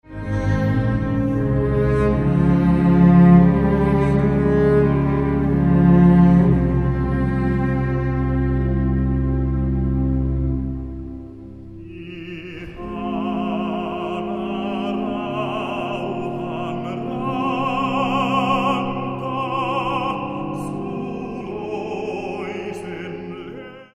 virsiä ja lauluja
laulu